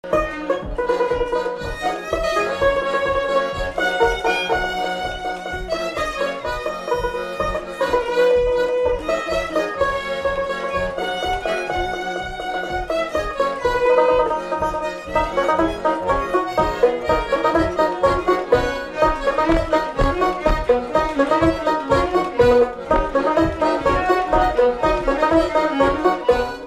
Instrumental
Pièce musicale inédite